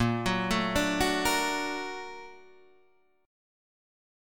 Bb+M7 chord